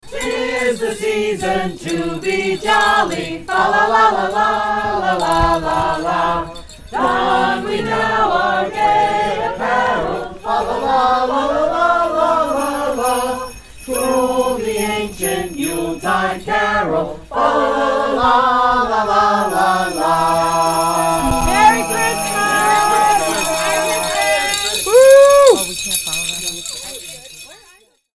Athens carolers: 'Tis the Season (Audio)